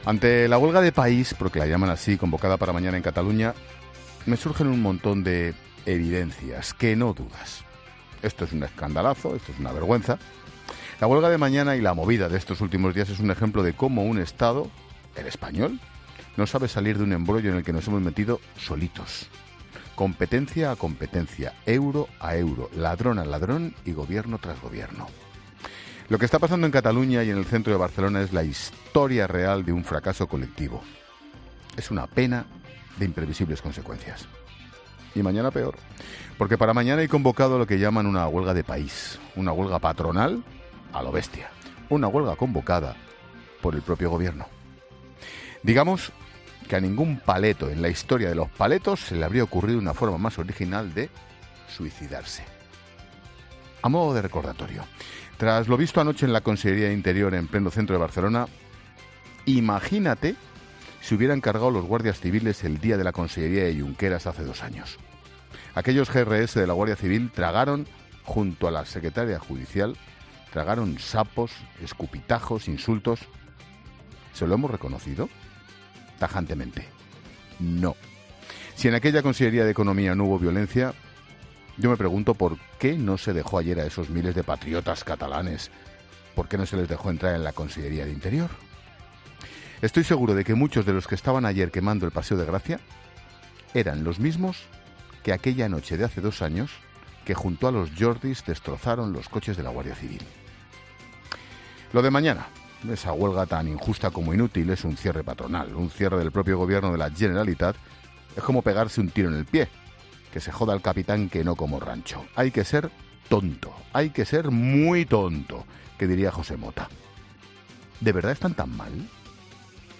Monólogo de Expósito
El presentador de La Linterna, Ángel Expósito, analiza la tensa situación tras tres jornadas de manifestaciones violentas